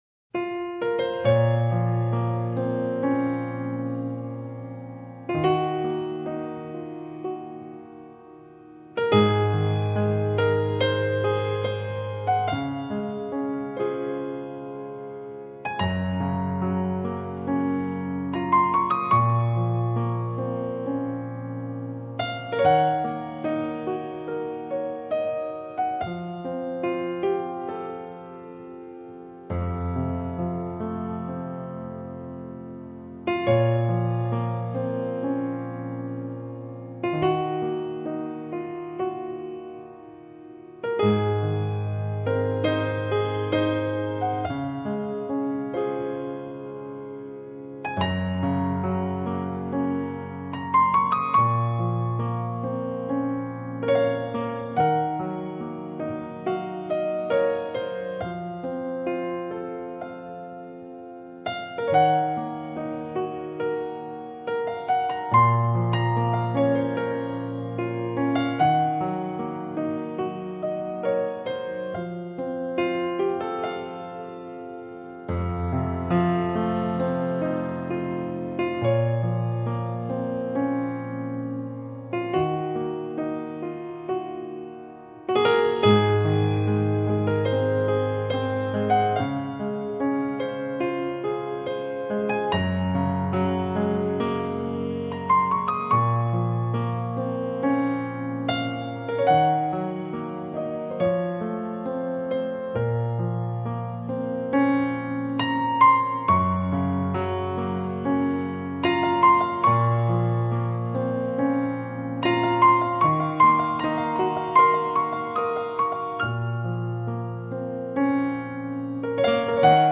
【聽新世紀鋼琴 訴說愛與離別】
* 以鋼琴獨奏的方式，舖陳夢境裏充滿感傷、與淒美愛情的歌曲。